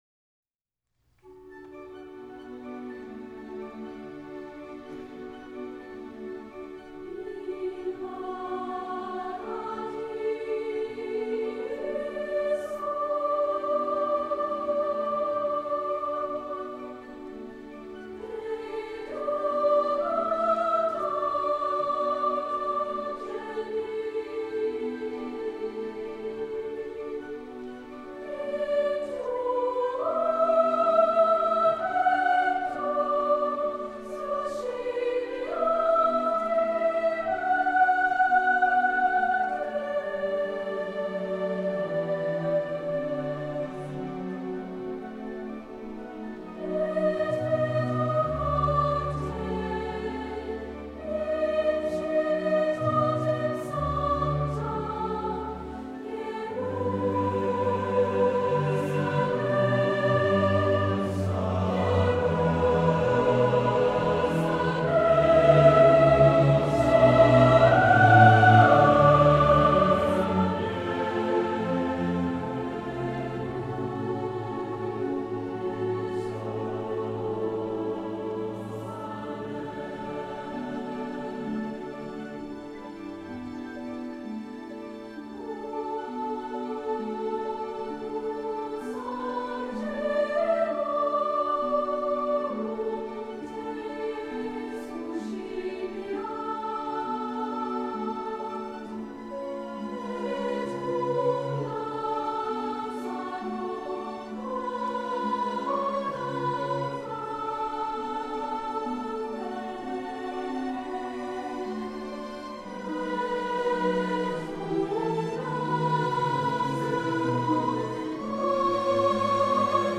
Oct. 29 – Farquhar Auditorium, Fauré Requiem etc. – with Victoria Chamber Orchestra